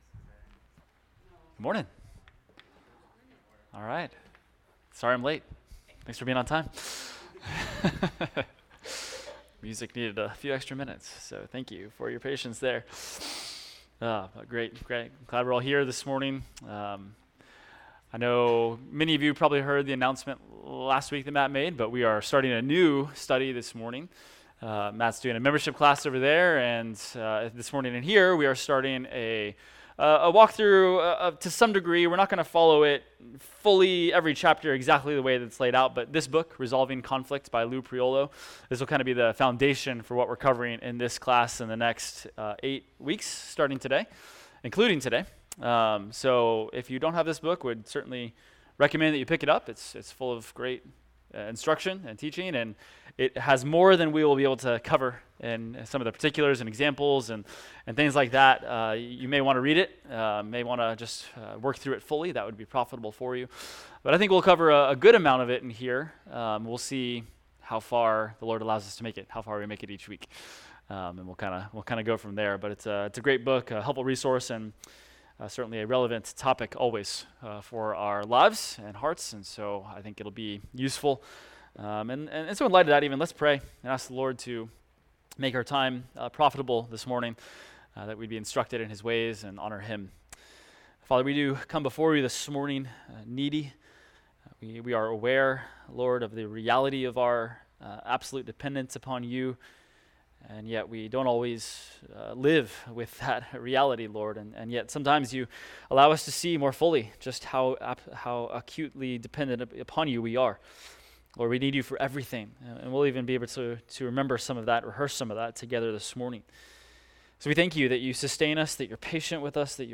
Adult Sunday School – Resolving Conflict – Week 1